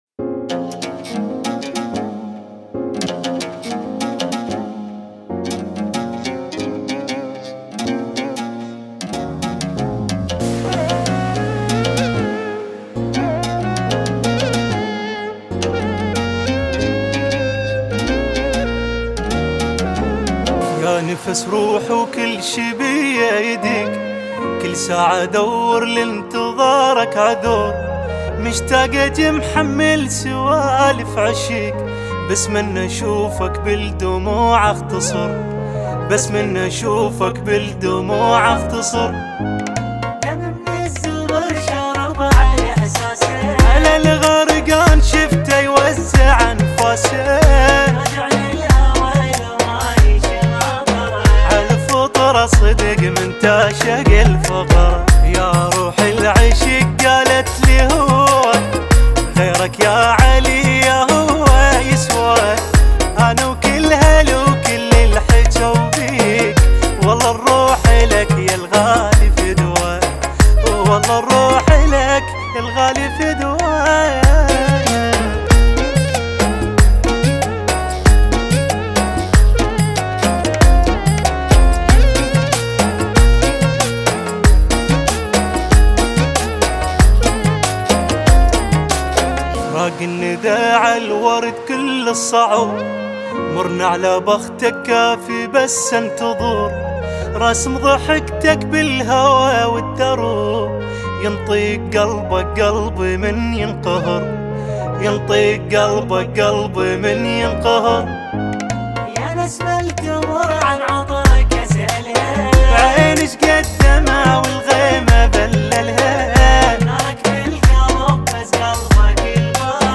قصيدة